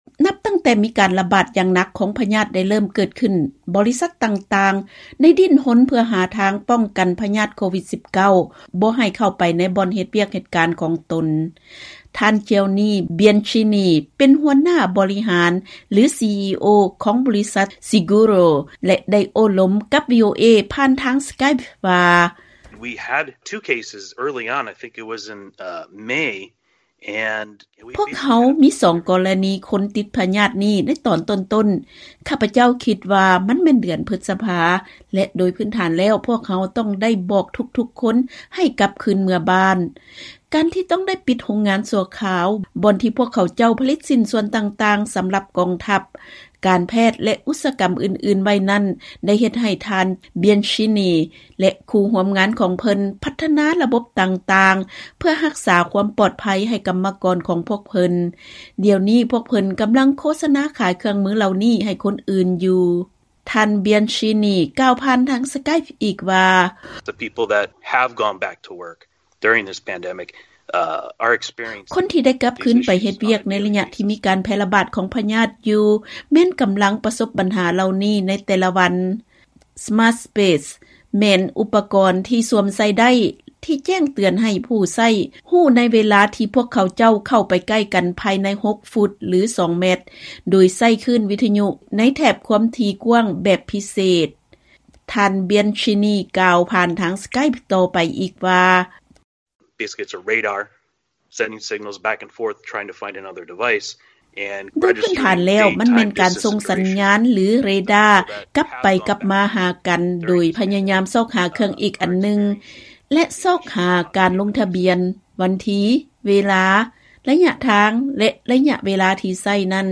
ເຊີນຟັງລາຍງານກ່ຽວກັບເທັກໂນໂລຈີທີ່ໃຊ້ຮັກສາຄວາມປອດໄພຫລີກລ້ຽງການຕິດໂຄວິດ-19